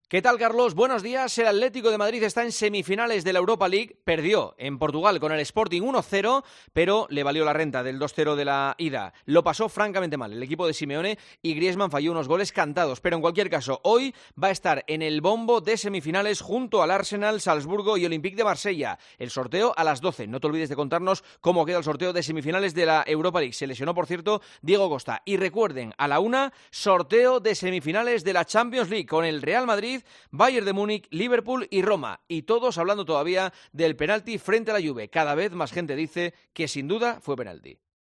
Escucha el comentario del director de 'El Partidazo de COPE', Juanma Castaño, en 'Herrera en COPE'